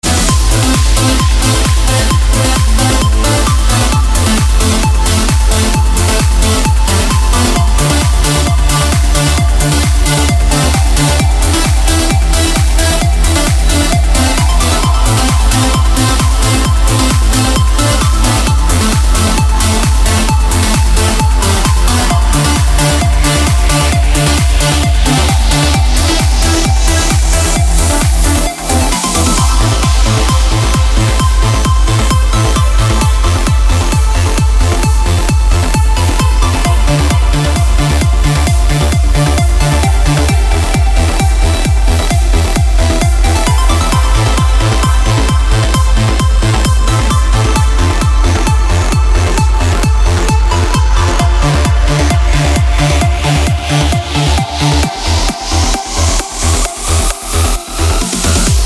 красивые
без слов
club
Trans